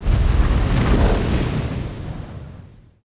大型宇宙飞船运动-YS070515.wav
通用动作/06交通工具/大气层外飞行器类/大型宇宙飞船运动-YS070515.wav
• 声道 單聲道 (1ch)